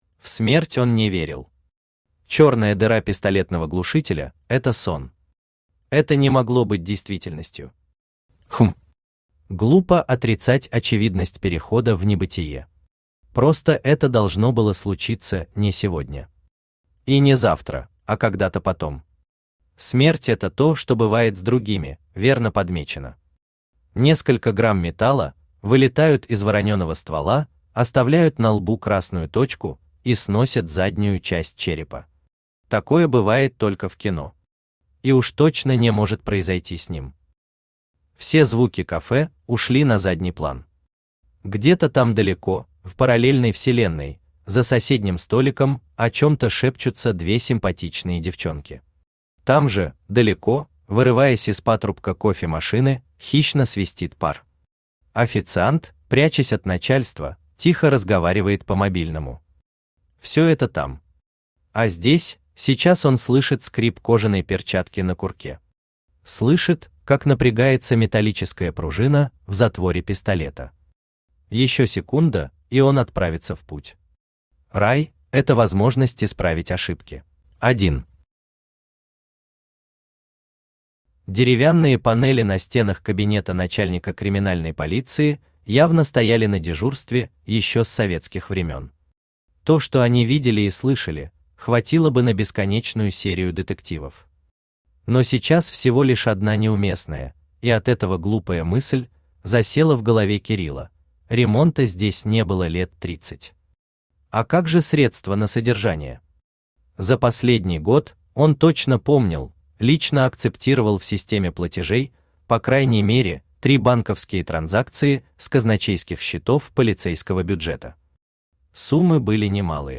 Аудиокнига Клоповник | Библиотека аудиокниг
Aудиокнига Клоповник Автор ШаМаШ БраМиН Читает аудиокнигу Авточтец ЛитРес.